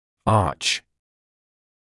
[ɑːʧ][аːч]дуга; зубная дуга (от dental arch)